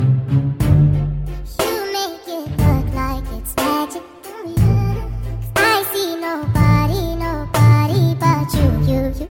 Pop & Rock
Sped Up & Slowed Versions